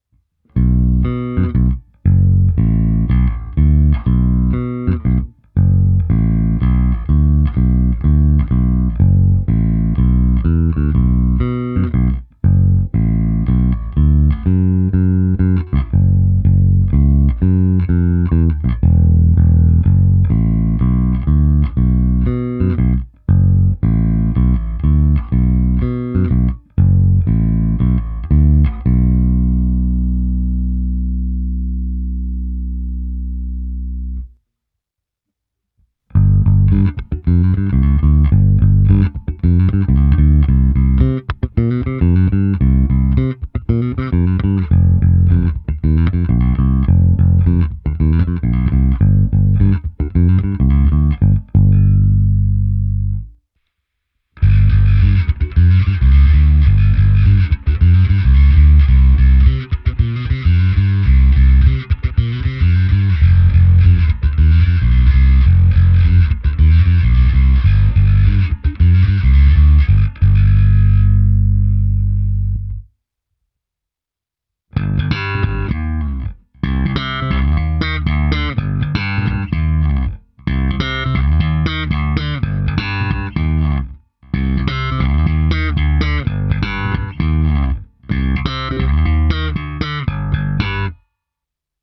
Zvuk je poměrně zvonivý, vrčí, výšek je dost.
Abych simuloval, jak hraje baskytara přes aparát, protáhnul jsem ji preampem Darkglass Harmonic Booster, kompresorem TC Electronic SpectraComp a preampem se simulací aparátu a se zkreslením Darkglass Microtubes X Ultra. Hráno na oba snímače, v nahrávce jsem použil i zkreslení a slapovou techniku.
Ukázka se simulací aparátu